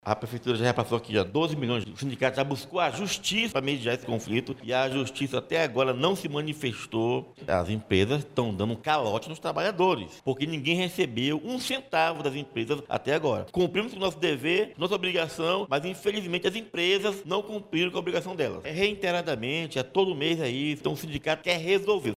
SONORA-1-GREVE-RODOVIARIOS-1-1.mp3